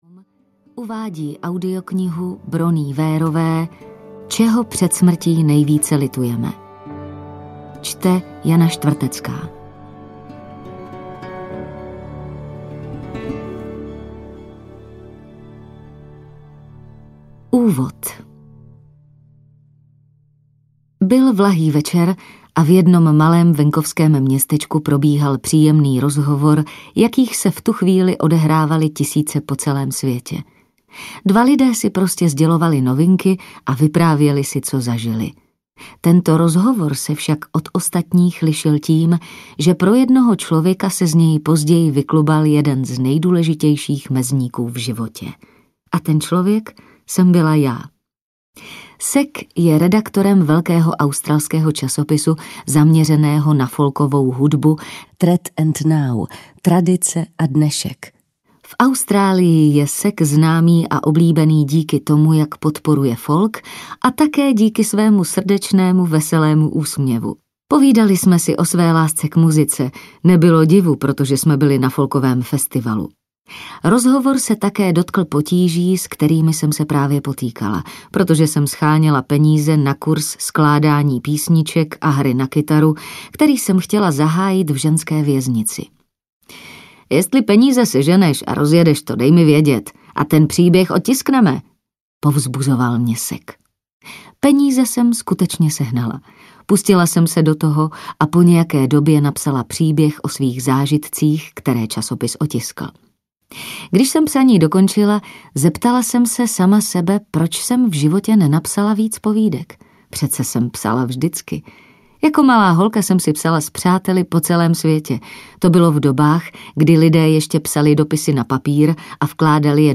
Čeho před smrtí nejvíc litujeme audiokniha
Ukázka z knihy